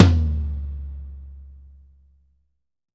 Index of /90_sSampleCDs/Sampleheads - New York City Drumworks VOL-1/Partition A/KD TOMS
FLOORBOOM -R.wav